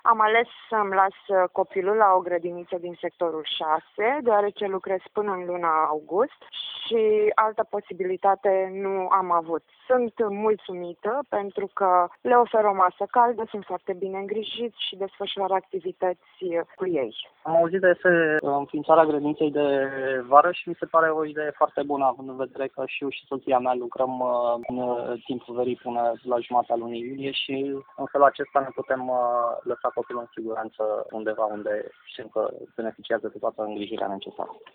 Părinţii sunt fericiţi că vor avea unde să îşi lase micuţii cât timp ei sunt la serviciu: